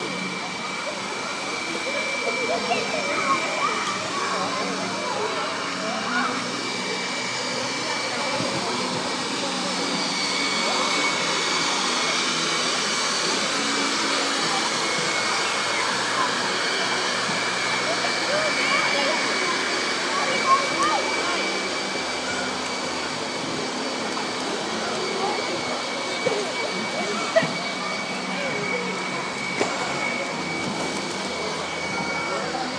En la piscina, hinchables deshinchando se